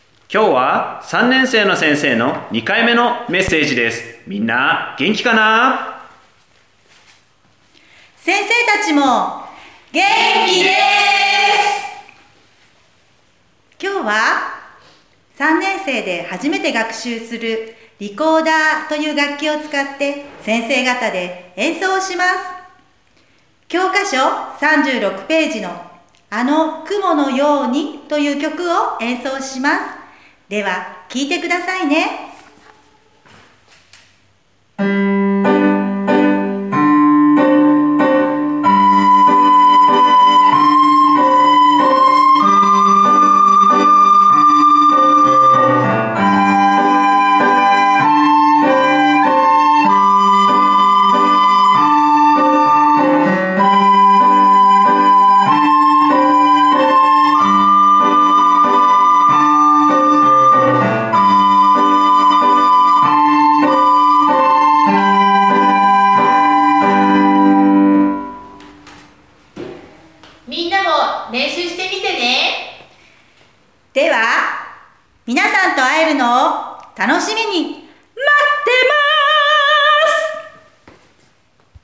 [ プレイヤーが見えない場合はこちらをクリック ] おやおや，きれいなメロディーが聞こえてきましたよ。